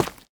Minecraft Version Minecraft Version snapshot Latest Release | Latest Snapshot snapshot / assets / minecraft / sounds / block / tuff_bricks / step2.ogg Compare With Compare With Latest Release | Latest Snapshot
step2.ogg